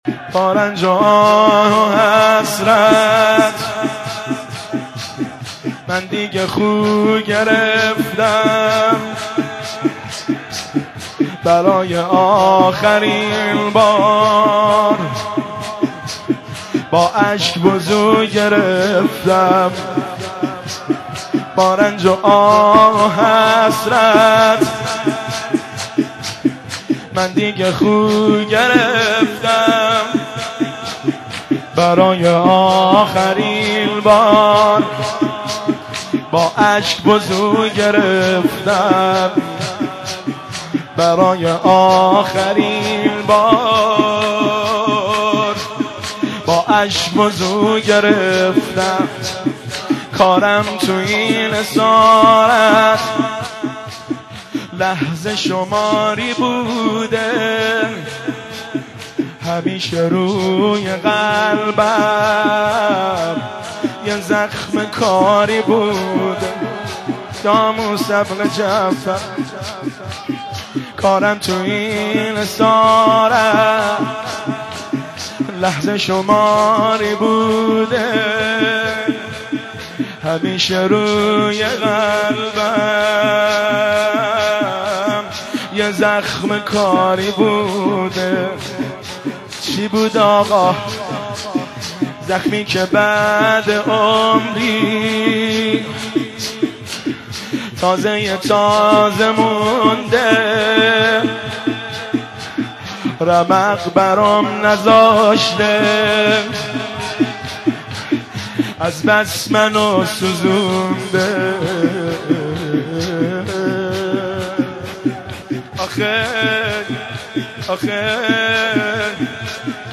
مداحی با رنج و آه و حسرت(شور)
شهادت امام کاظم(ع) 1390
هیئت بین الحرمین